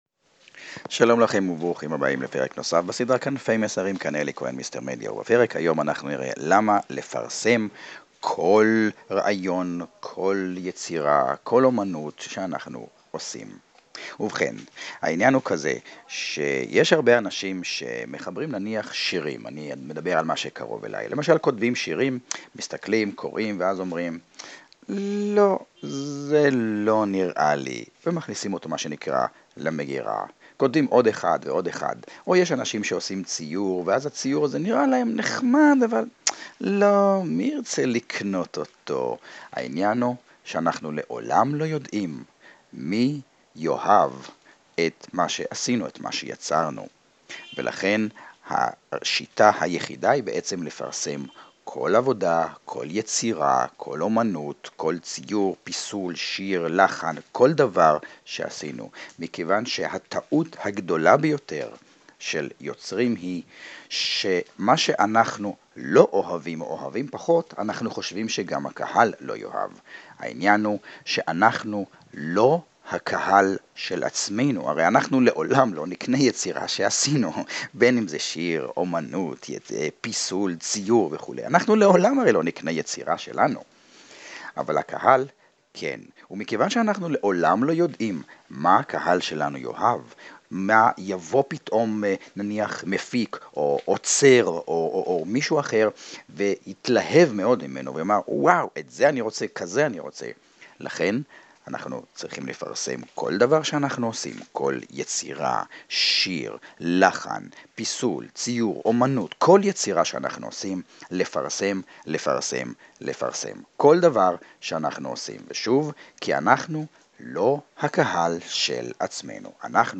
רוב ההקלטות ב-"תחנת הרדיו הפרטית" בוצעו במכשיר הסמארטפון והועלו לכאן ללא כל עריכה, וכך גם אתה יכול להקליט את המסר שלך, ללחוץ עוד קליק או 2, ולשדר את עצמך והמסר שלך - לעולם!